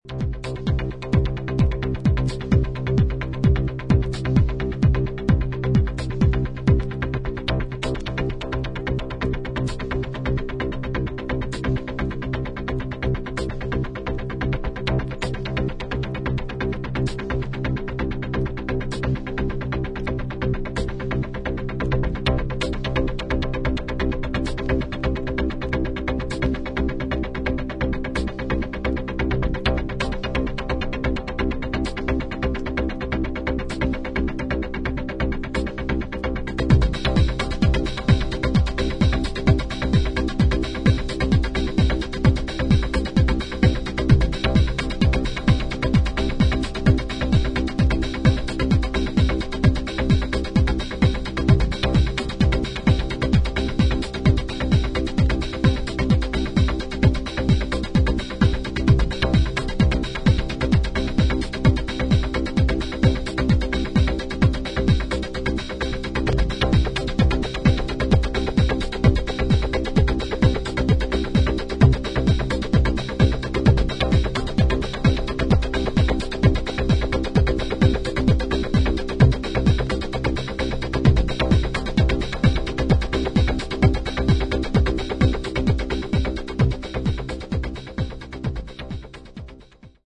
シンプルな編成ながら深みが感じられるエクスペリメンタル的要素が垣間見える90"テクノ傑作